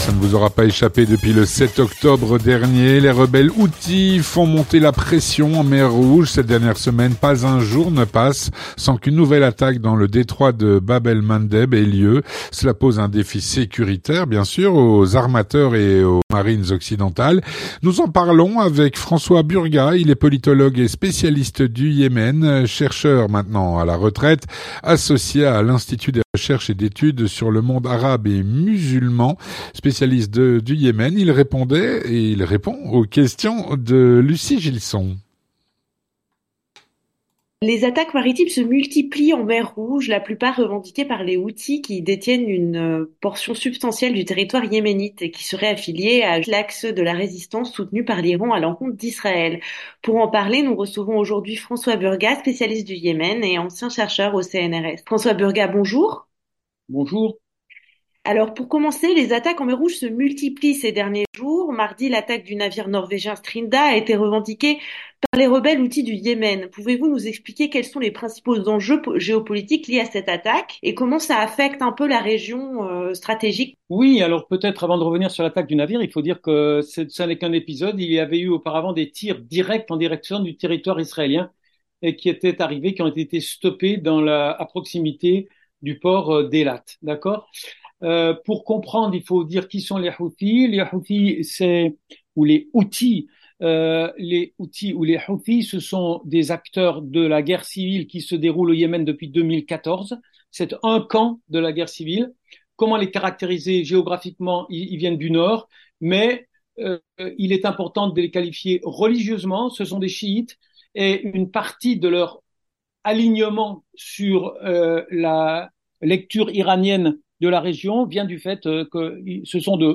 Avec François Burgat, politologue et spécialiste du Yémen, chercheur, maintenant retraité, associé à l'Institut de recherches et d'Études sur le Monde Arabe et Musulman (IREMAM).